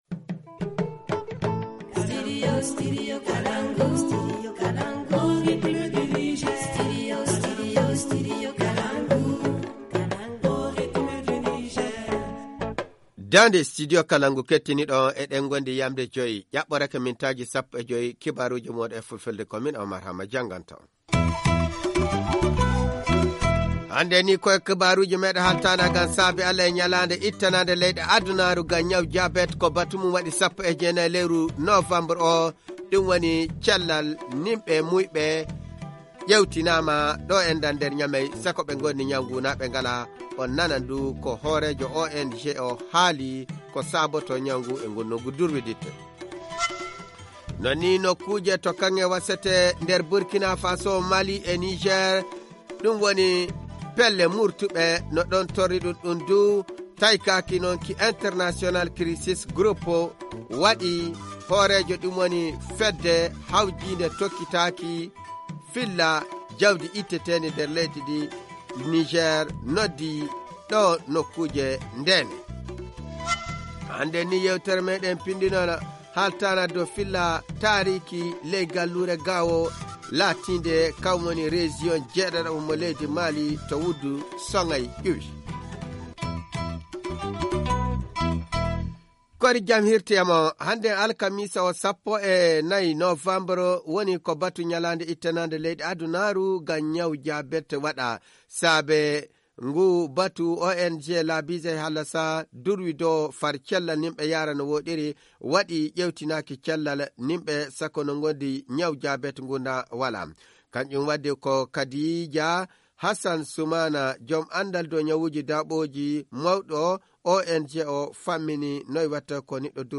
Le journal du 14 novembre 2019 - Studio Kalangou - Au rythme du Niger